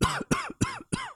Cough X 4.wav